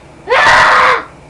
Frustrated Boy Sound Effect
Download a high-quality frustrated boy sound effect.
frustrated-boy.mp3